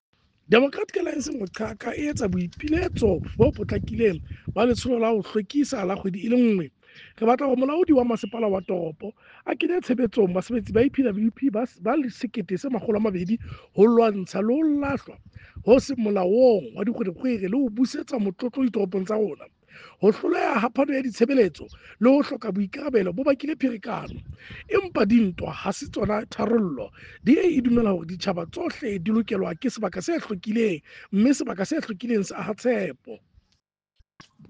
Sesotho soundbite by Cllr Kabelo Moreeng.